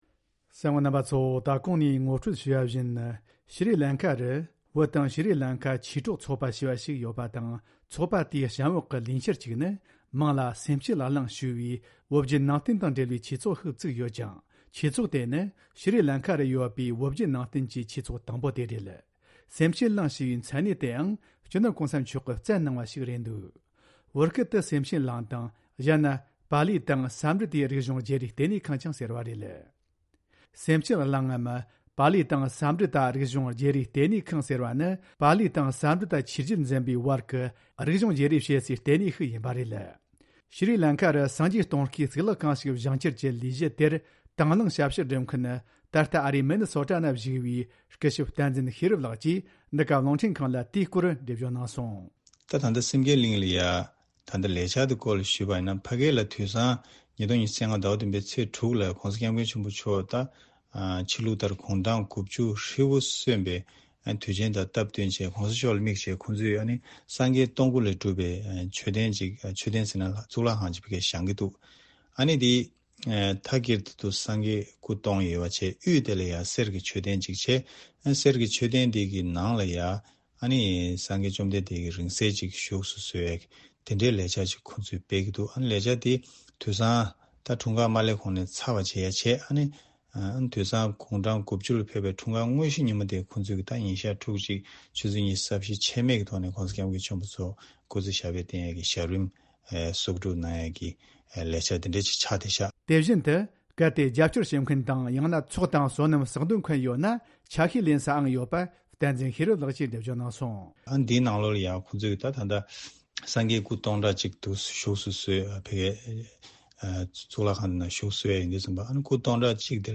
བཅར་འདྲི་དང་གནས་ཚུལ་ཕྱོགས་བསྒྲིགས་བྱས་པར་གསན་རོགས་གནོངས།